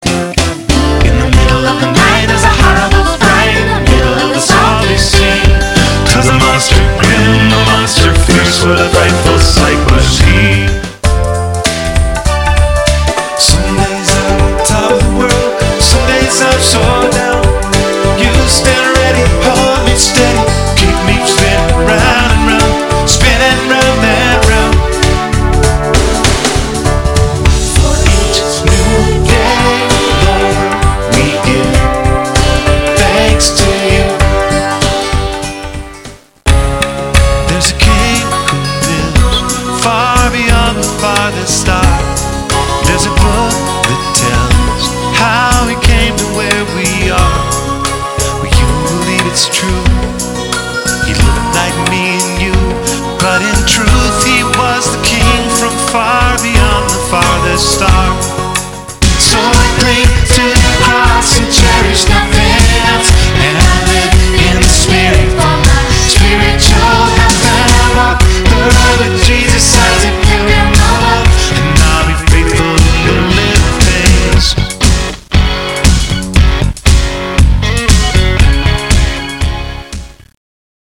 is the gospel in a song
GOD ROCKS THE WORLD is a lullaby for kids of all ages.
a hip-hop song of praise.